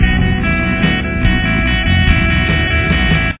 Verse Riff